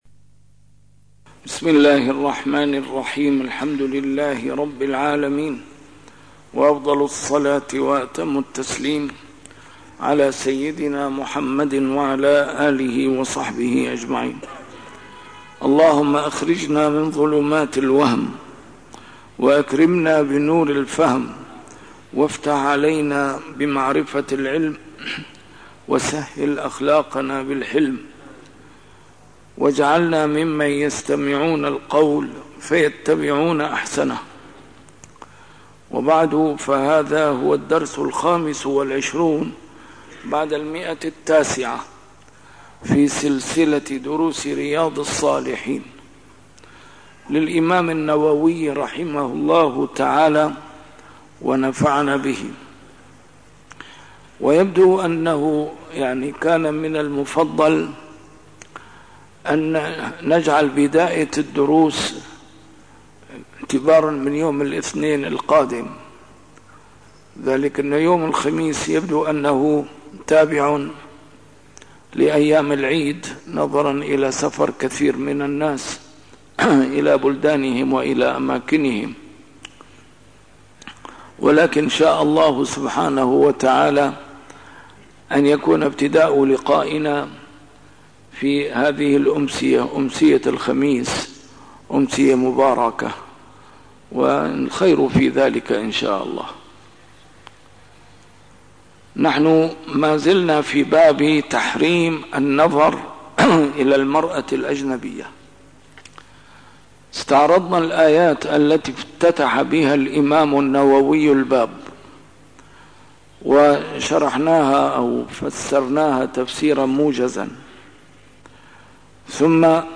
A MARTYR SCHOLAR: IMAM MUHAMMAD SAEED RAMADAN AL-BOUTI - الدروس العلمية - شرح كتاب رياض الصالحين - 925- شرح رياض الصالحين: تحريم النظر إلى المرأة الأجنبية